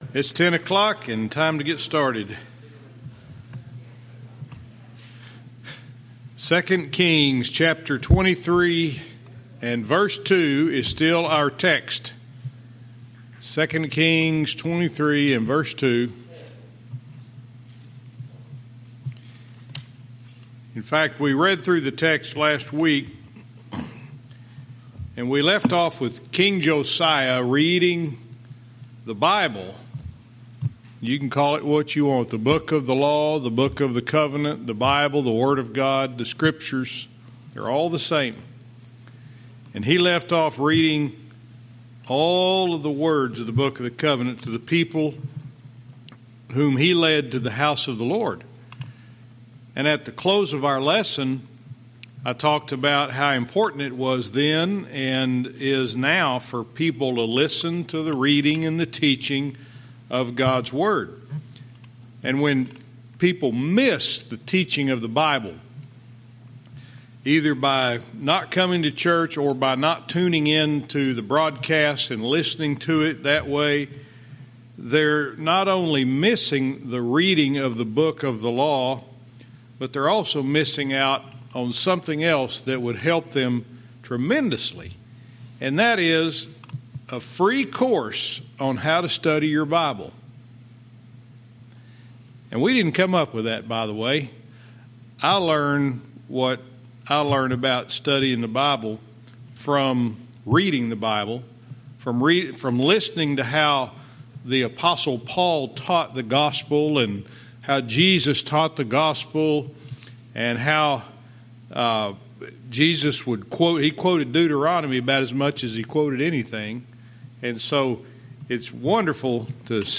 Verse by verse teaching - 2 Kings 23:2-3